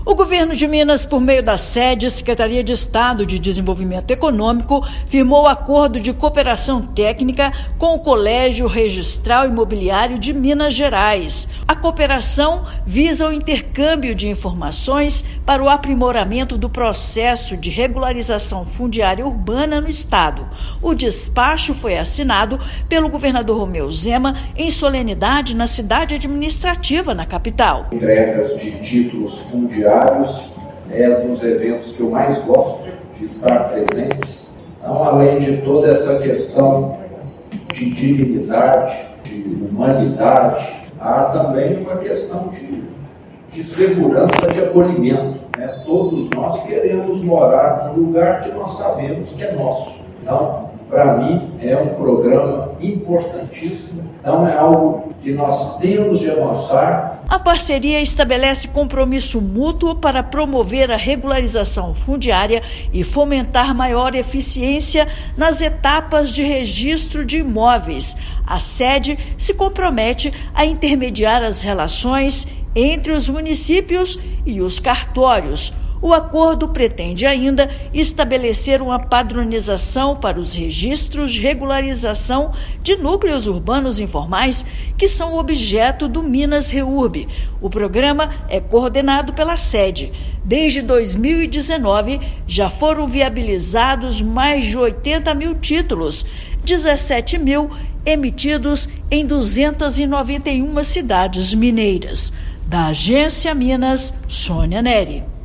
Acordo de Cooperação Técnica entre o Estado e o Colégio Registral Imobiliário de Minas Gerais (Cori-MG) estabelece a troca de informações, tornando mais eficiente o registro de imóveis. Ouça matéria de rádio.